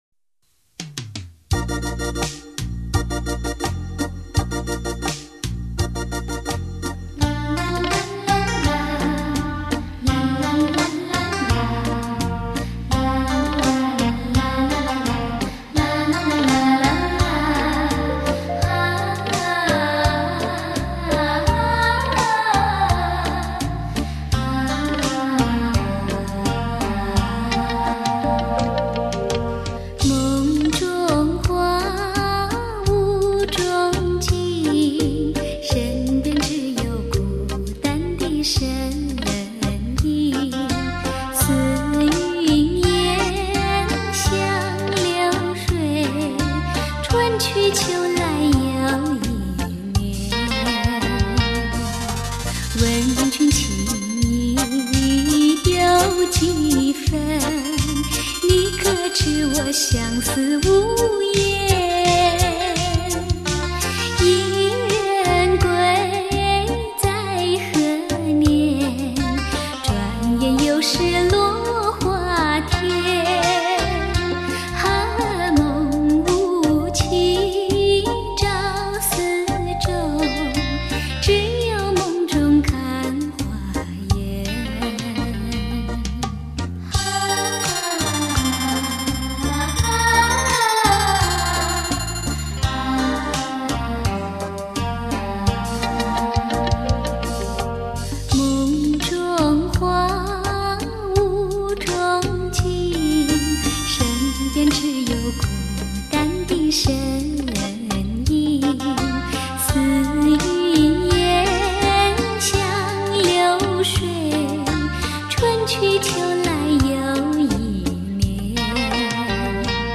专辑类型：录音室专辑
擅长抒情的玉女歌手，声音甜美，
声线清纯，富于发挥，韵味浓厚。